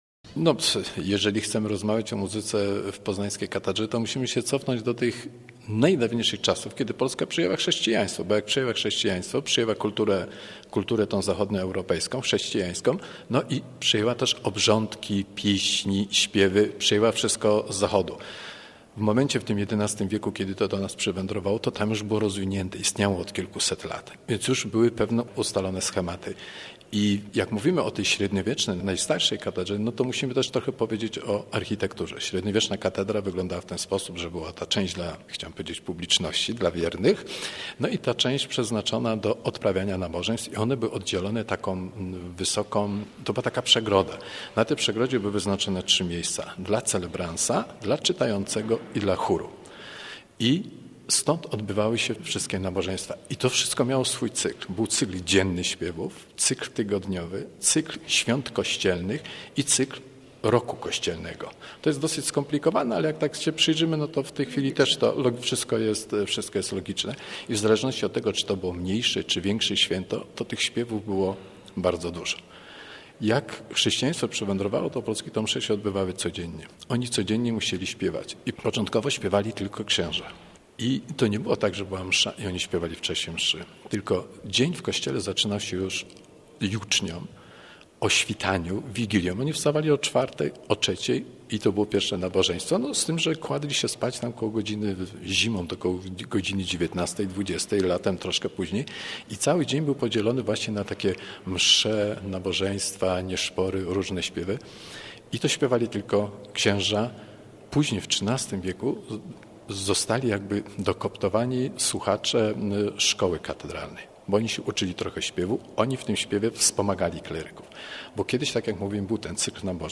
rozmawiała